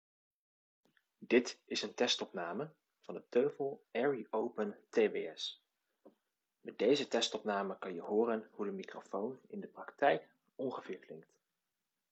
Waar ik ook erg over te spreken ben, is de opnamekwaliteit.
Er is geen ruis, geen echo, het werkt prima in de wind, of zelfs in een luidruchtige auto.